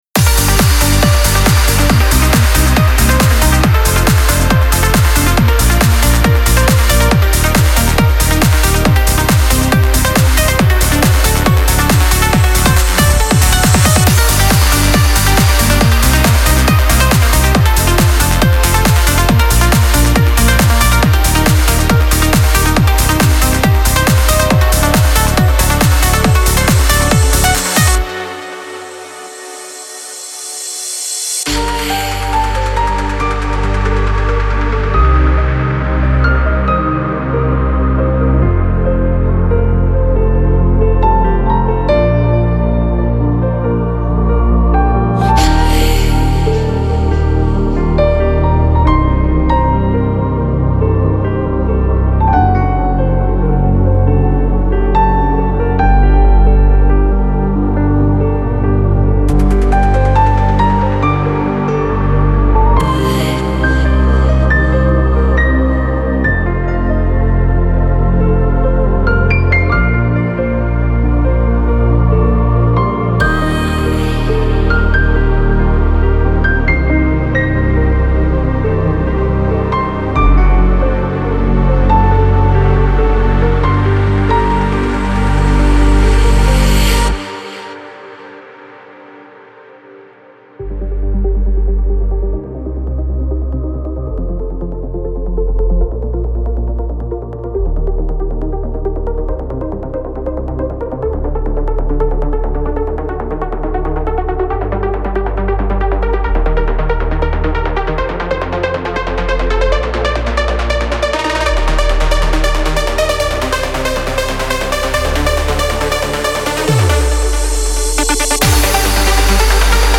موسیقی کنار تو
امید‌بخش , پر‌انرژی , رقص , موسیقی بی کلام , ویولن